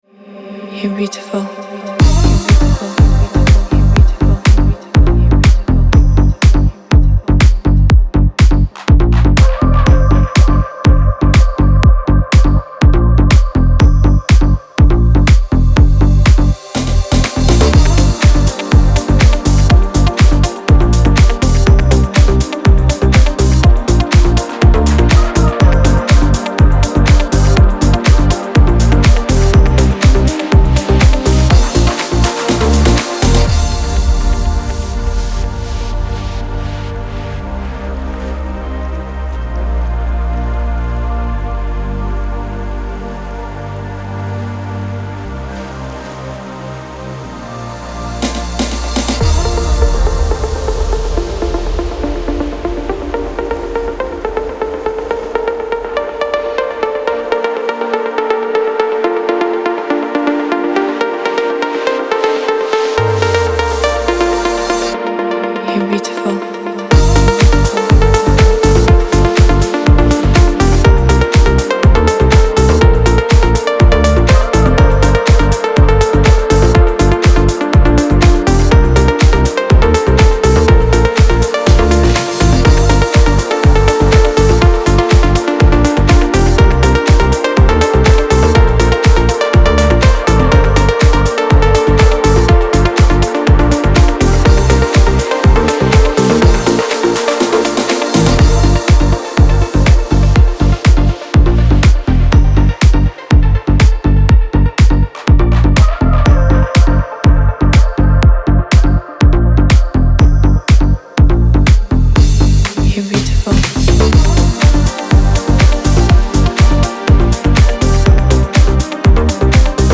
это энергичная и вдохновляющая песня в жанре поп-рок